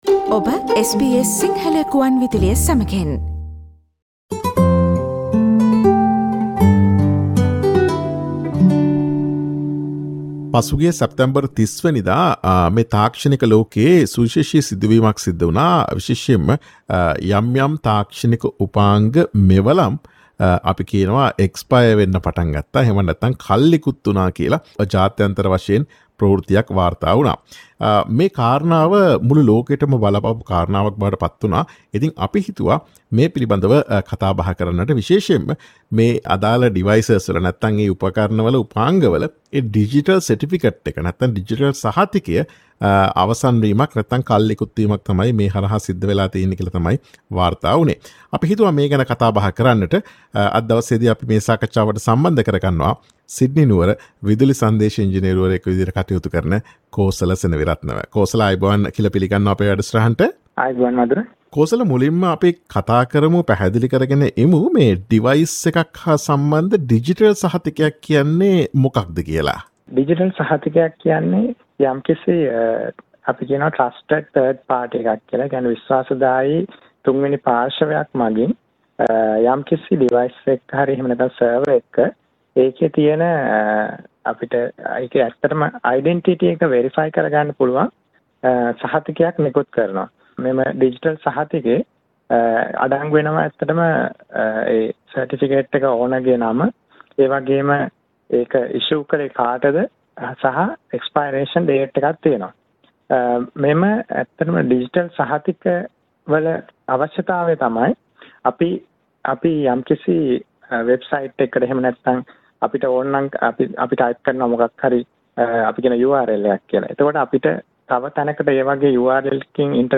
අන්තර්ජාලයට ප්‍රවේශ වීම ඉවත් කර තීරණාත්මක ඩිජිටල් සහතිකයක් කල් ඉකුත් වූ හෙයින් පැරණි උපකරණ මිලියන ගණනක් 2021 සැප්තැම්බර් 30 වන දින සිට අක්‍රිය වී තිබේ. SBS සිංහල ගුවන් විදුලිය ඒ පිළිබඳ සිදුකළ සාකච්ඡාවට සවන් දෙන්න.